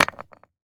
default_stone_hit.ogg